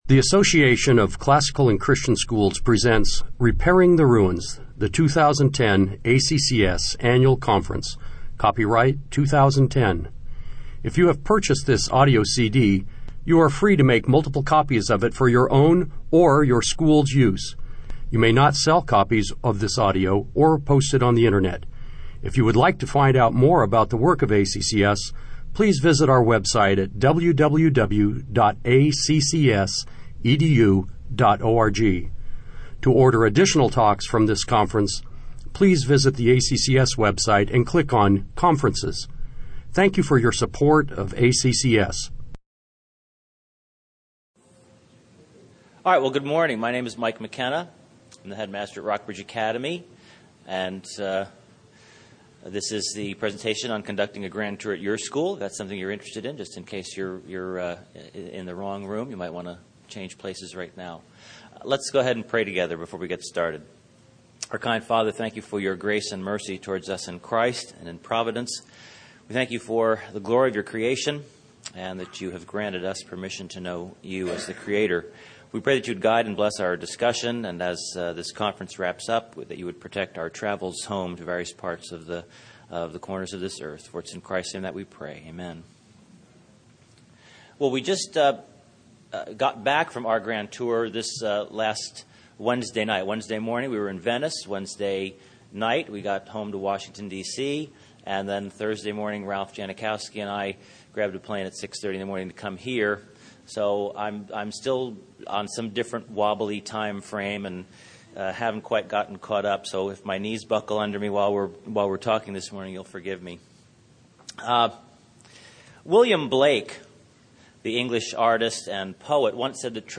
2010 Workshop Talk | 1:02:15 | 7-12